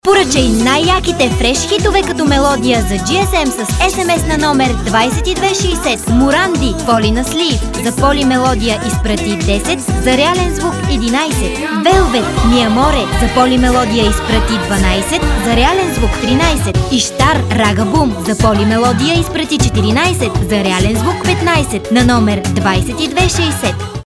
Sprecherin bulgarisch für Werbung, TV, Hörfunk, Industrie,
Sprechprobe: Industrie (Muttersprache):
Professional female voice over artist from bulgary